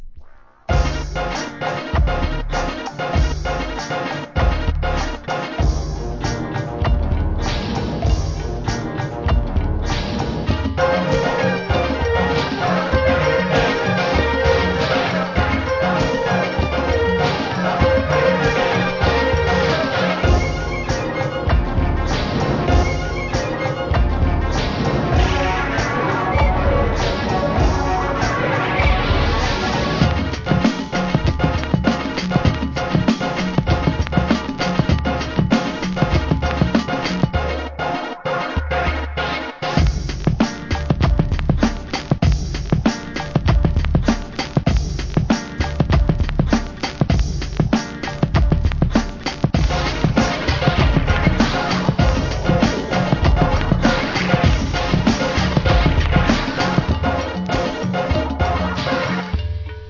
HIP HOP/R&B
ブレイクビーツ、RAP等の珍しいコンピレーションです。